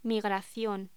Locución: Migración
voz